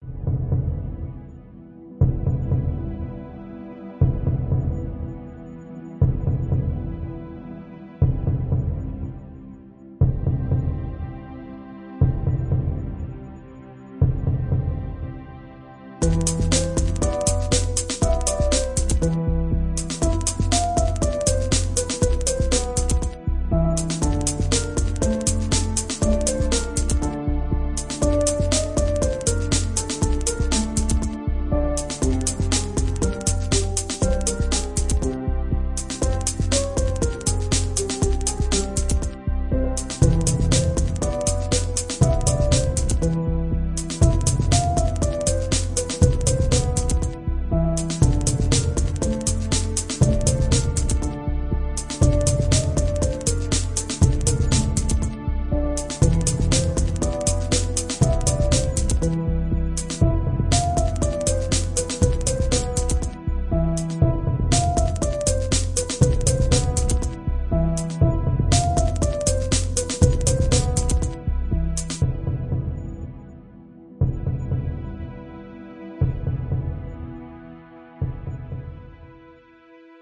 描述：我在钢琴上录制了一些片段，并使用Acoustic Piano语音将它们放在Music Maker的虚拟合成器中。
标签： 钢琴 背景 器乐 电影 电影
声道立体声